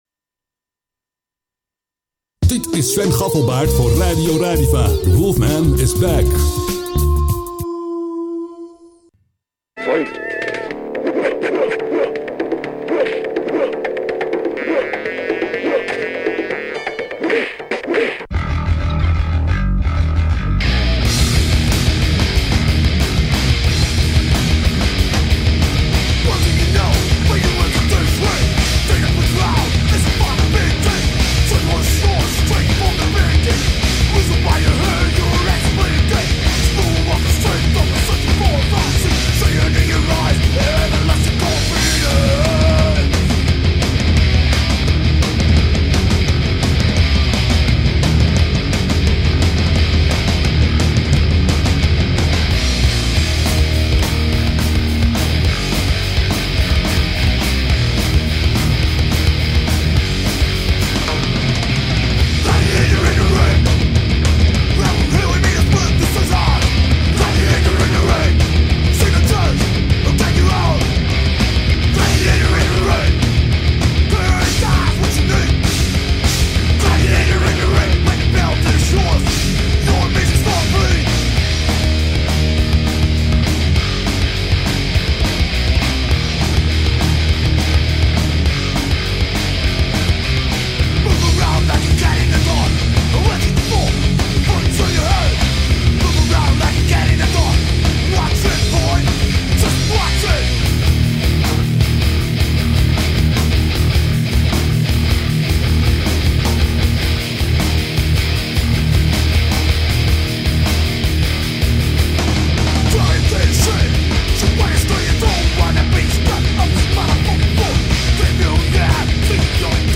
interview Eightball